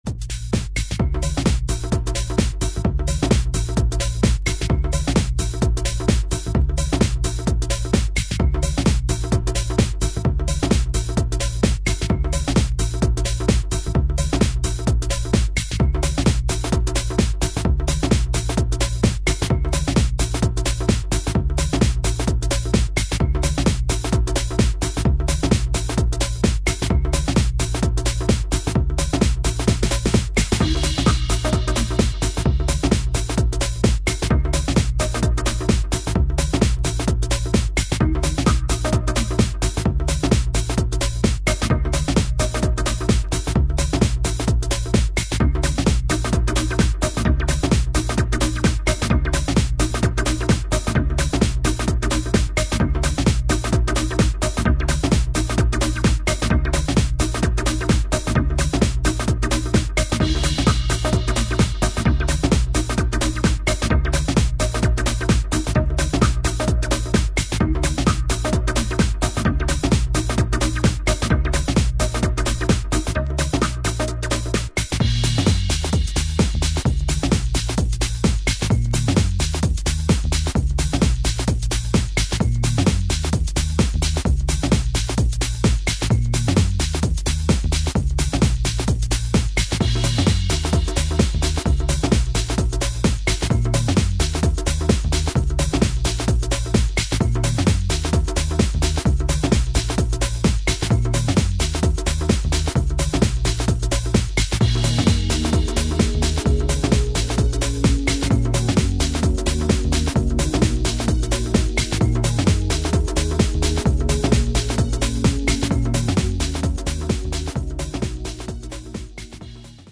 ベースの効いた重厚なグルーヴにデトロイト感のある展開が魅力的な